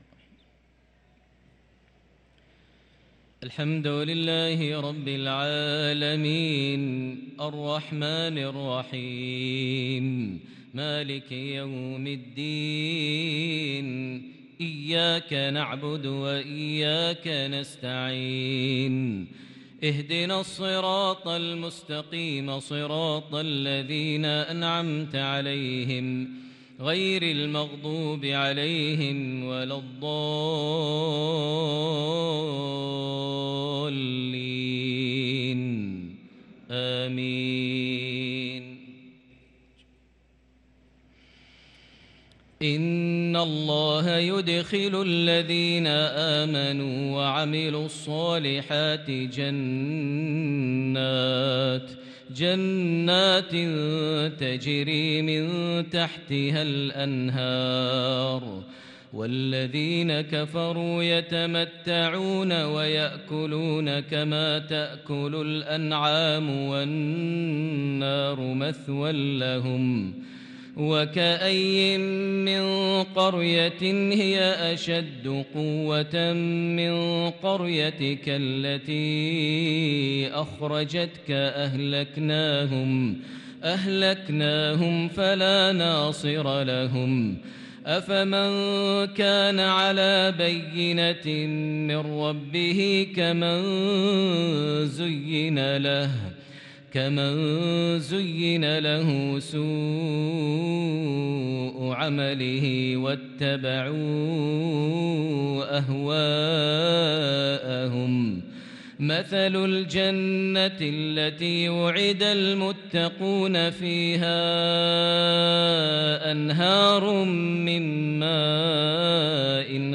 صلاة العشاء للقارئ ماهر المعيقلي 11 صفر 1444 هـ